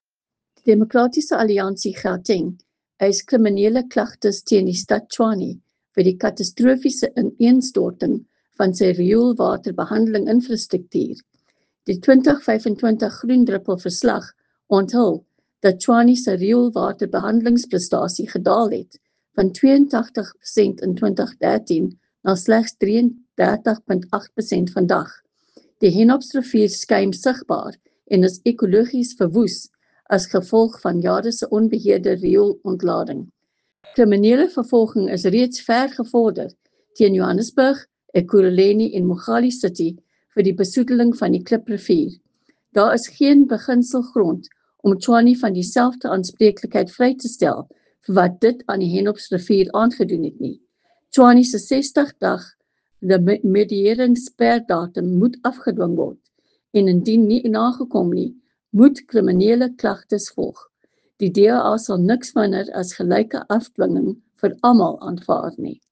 Issued by Leanne De Jager MPL – DA Gauteng Spokesperson for Environment
Note to Editors: Attached please find a soundbite in English